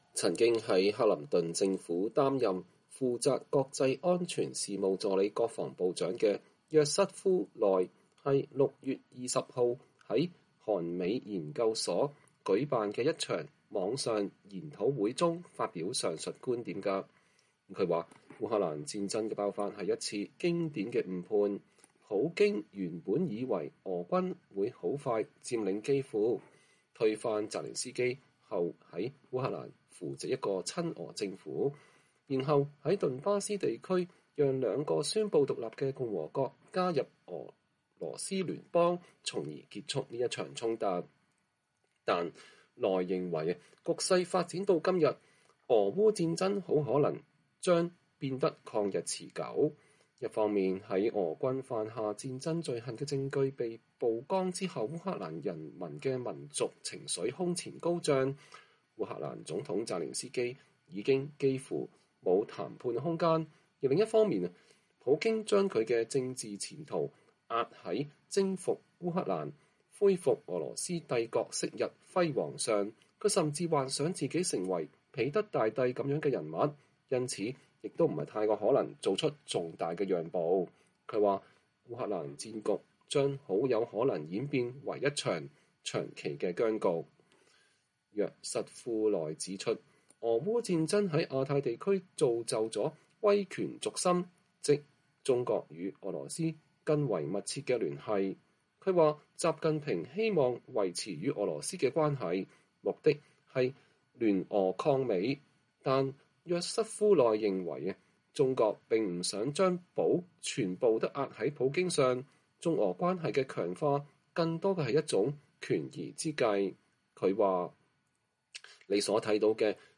曾在克林頓政府擔任負責國際安全事務助理國防部長的約瑟夫·奈是6月20日在韓美研究所(Institute for Corean-American Studies, ICAS)舉辦的一場線上討論會中發表上述觀點的。